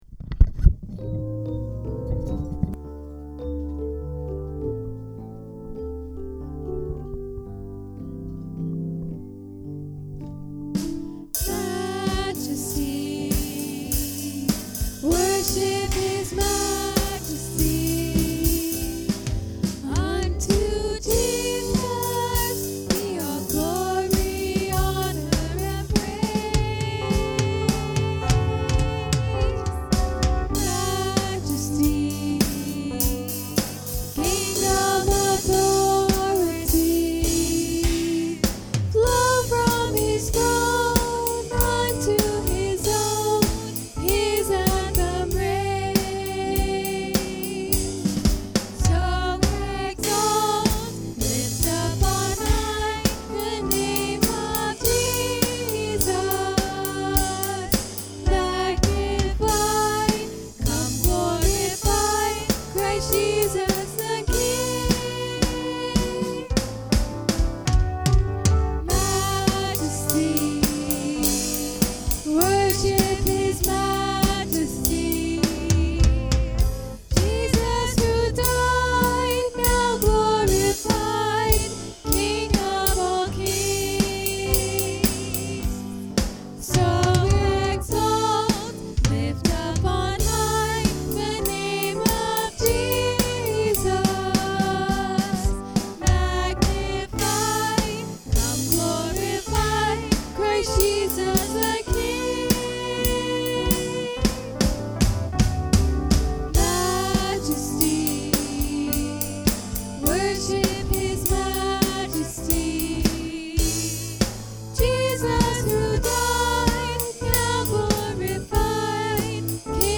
Colossians 1:15-23 Service Type: Sunday Morning Worship Paul give us great theology and biblical truth the refute this bad teaching.